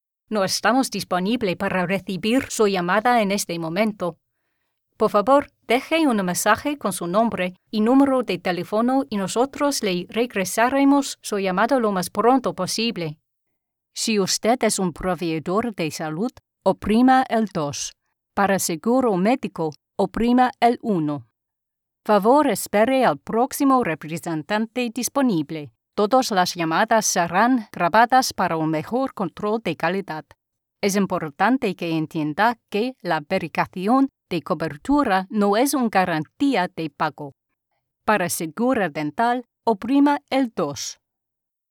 English (British)
Friendly
Convincing
Natural